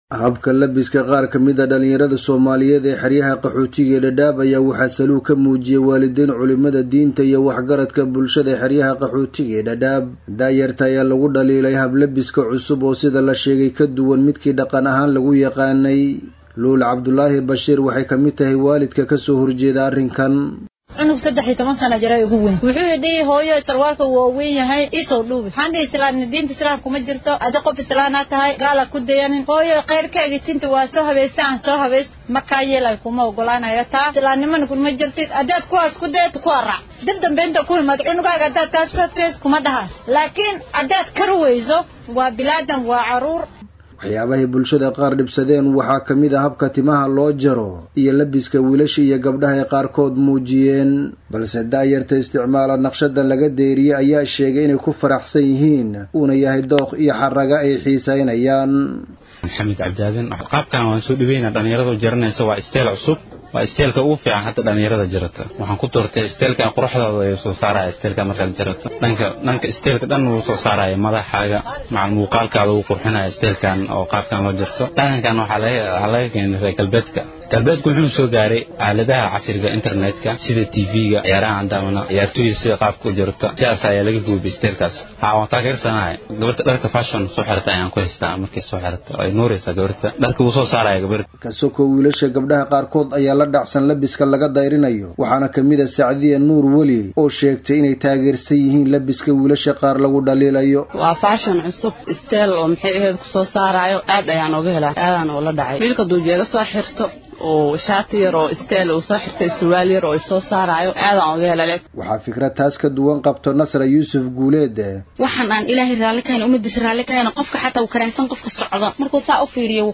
warbixintan Dadaab ka soo diray